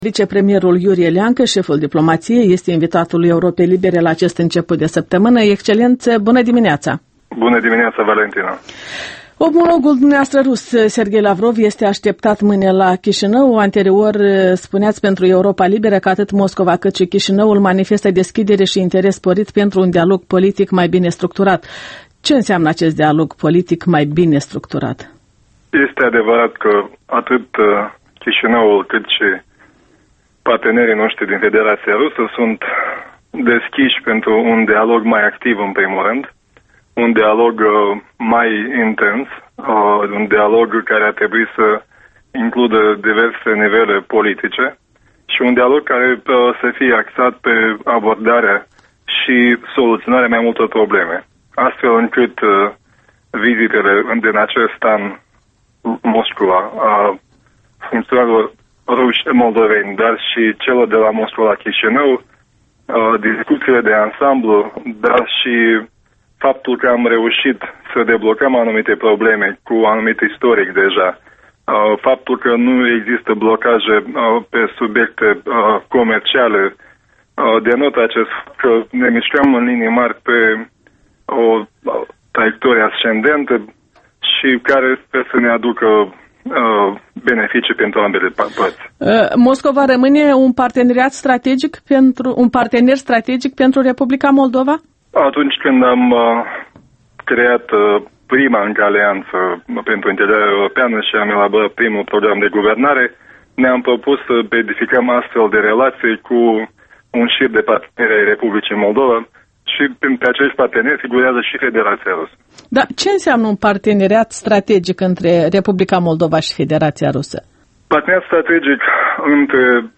Interviul dimineții la Europa Liberă: cu vicepremierul Iurie Leancă